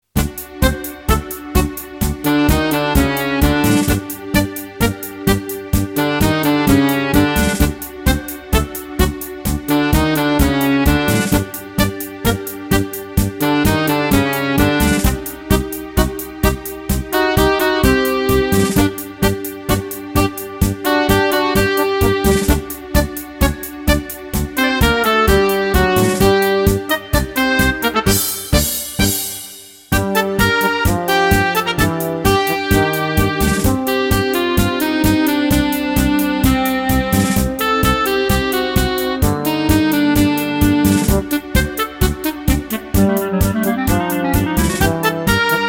- tango